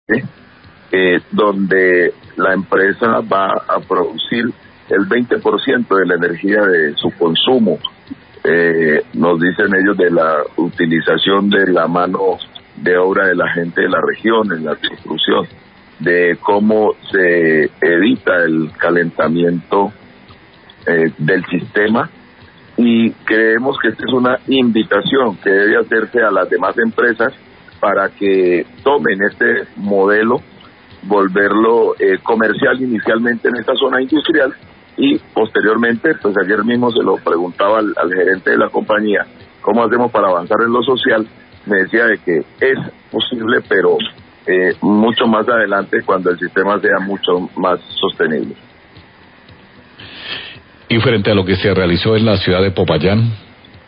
Gobernador del Cauca habla de la Granja Solar instalada en Colombina
Radio